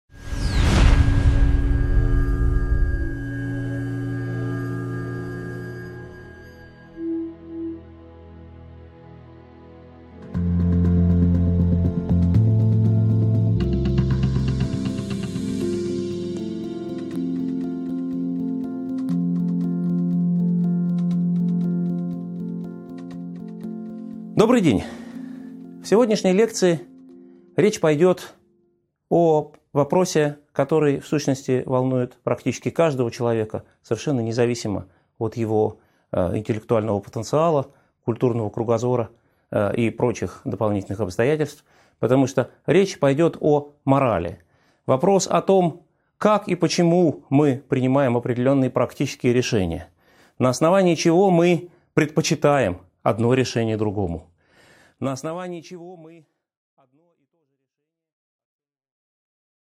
Аудиокнига 11.1 Типология этических учений | Библиотека аудиокниг